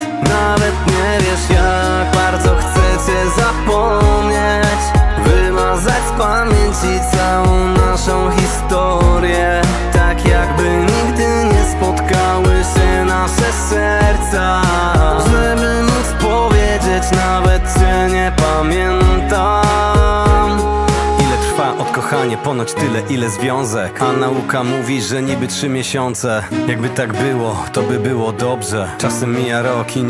Kategoria POP